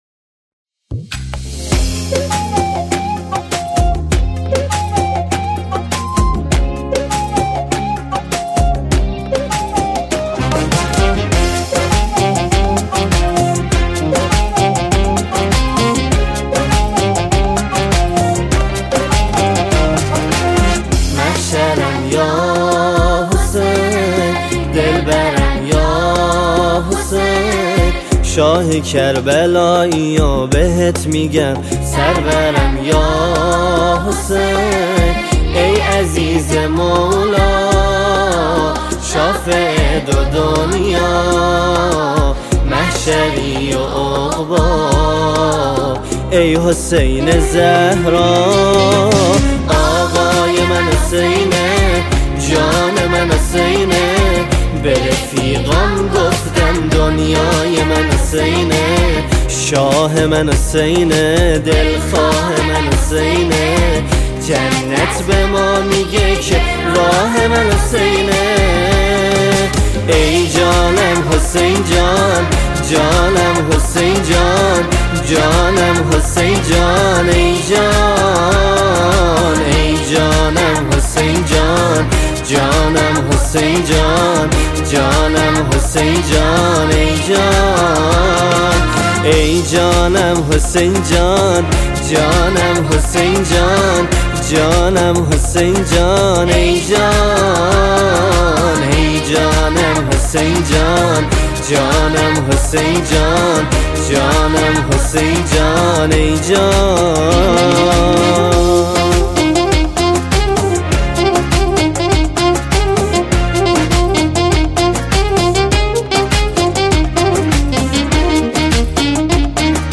موسیقی شاد
ژانر: سرود ، سرود مذهبی ، سرود مناسبتی